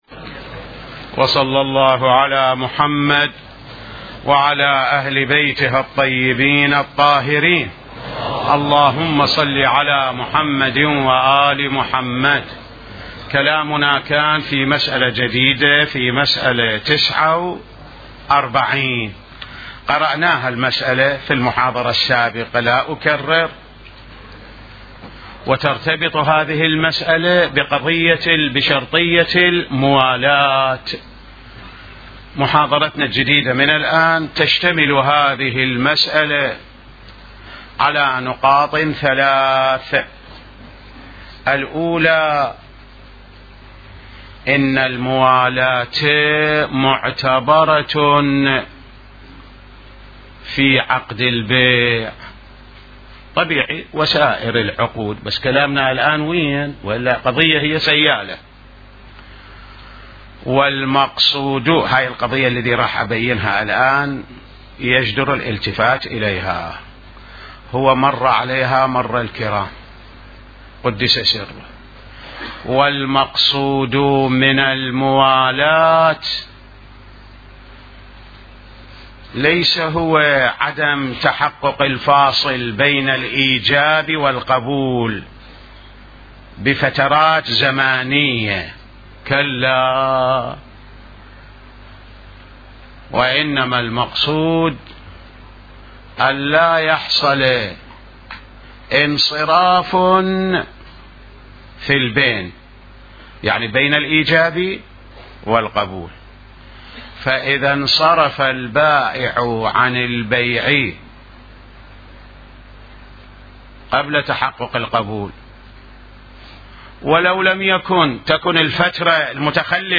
بحث الفقه